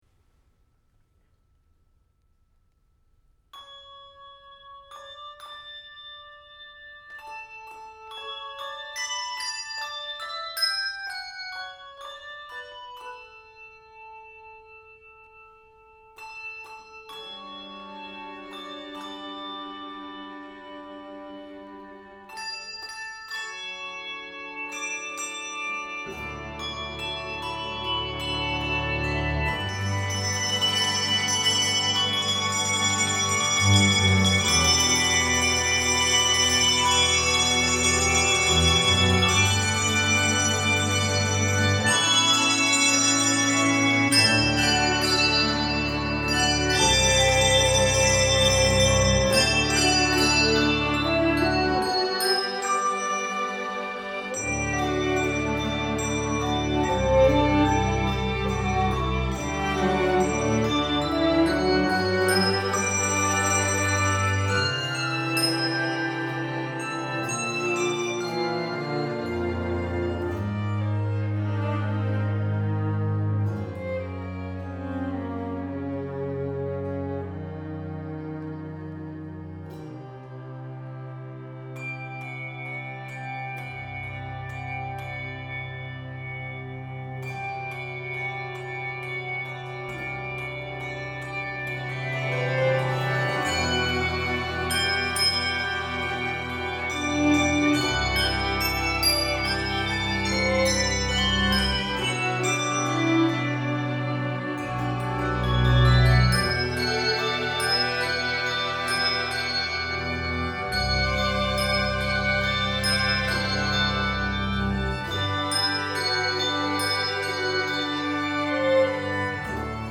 String Quartet and Organ